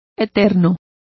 Complete with pronunciation of the translation of perpetual.